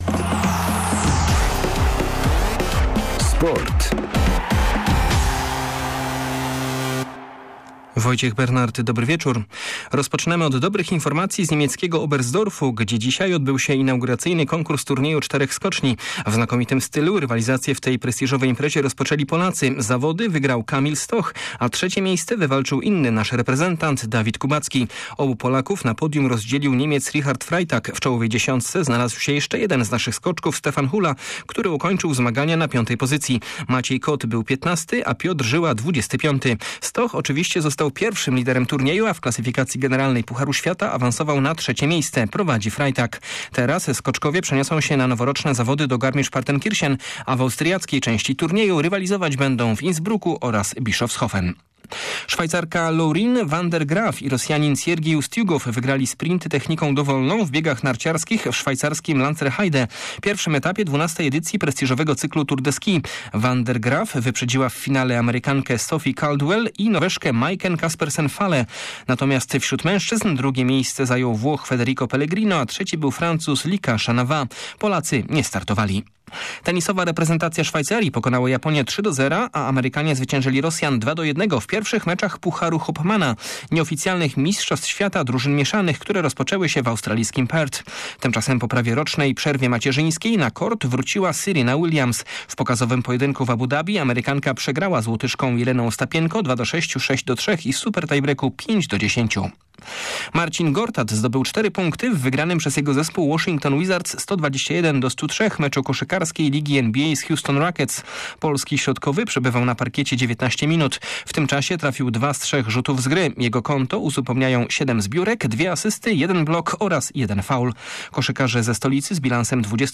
30.12 serwis sportowy godz. 19:05